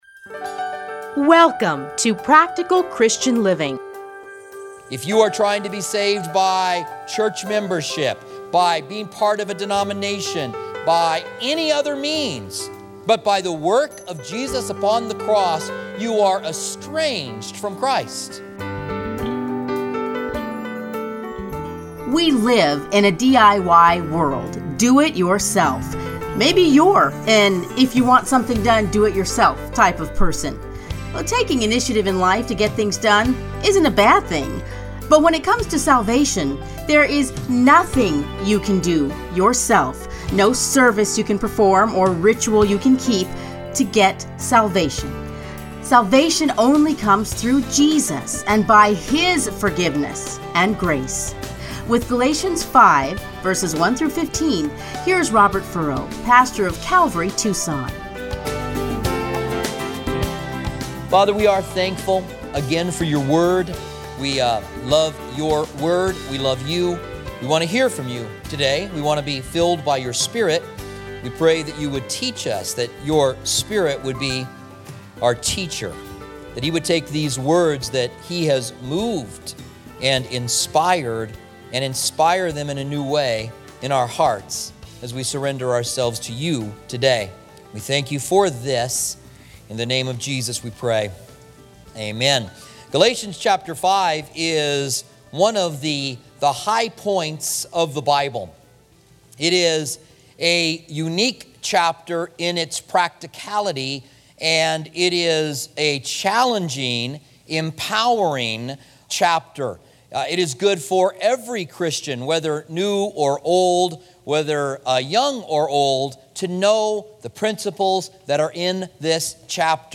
Listen here to his commentary on Galatians.